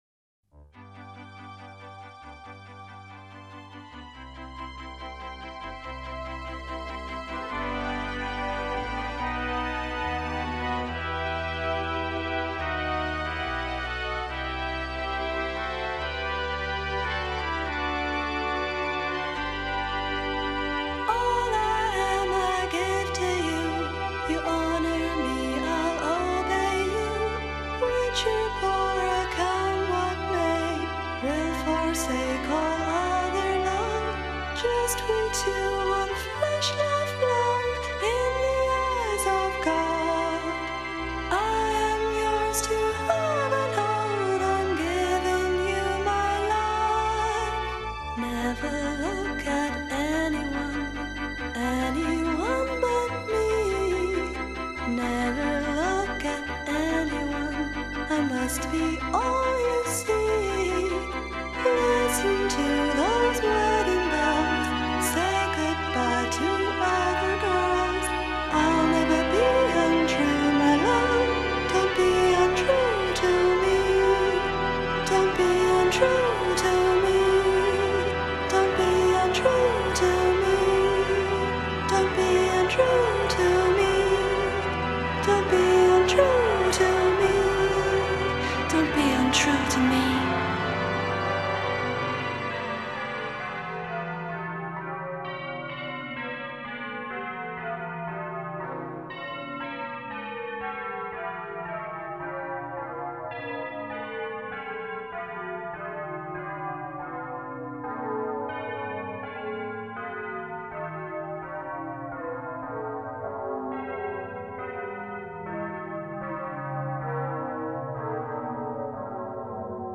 Full song, mono